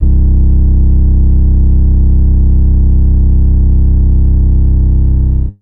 WeirdSub_YC.wav